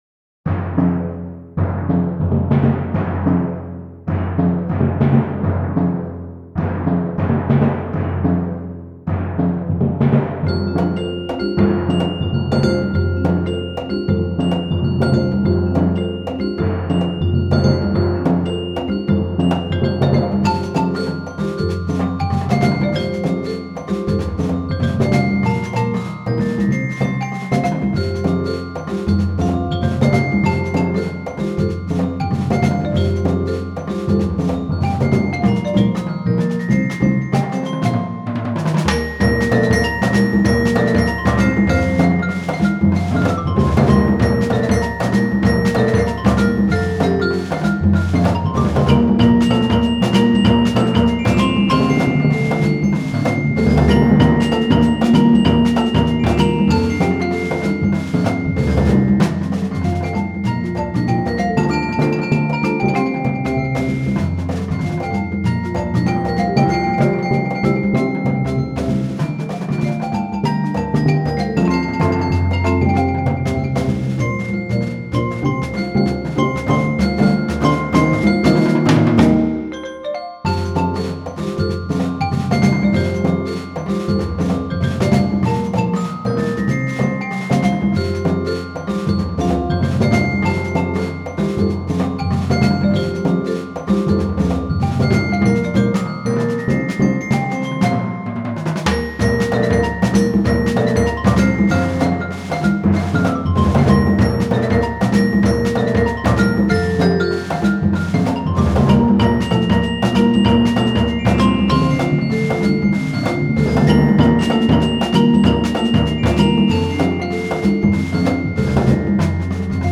Voicing: Percussion Octet